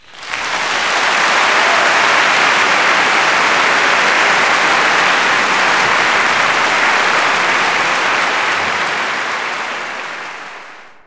clap_037.wav